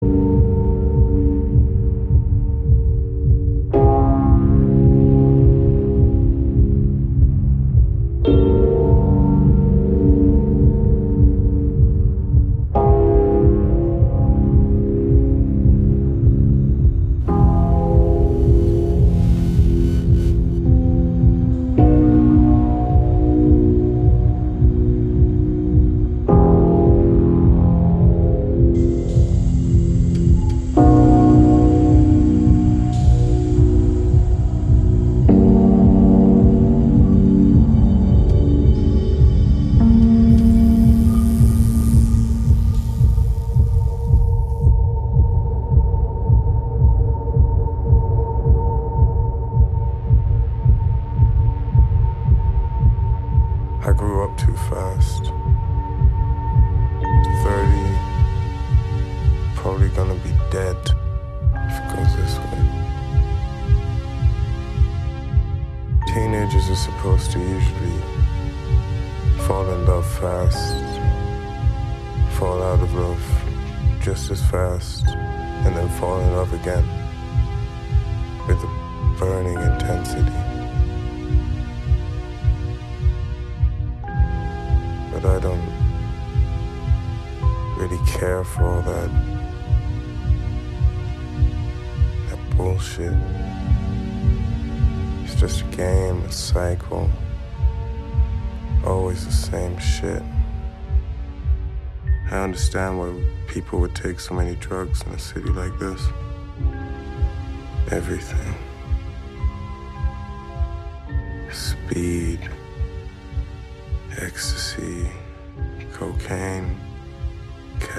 a deadpan spoken word vocal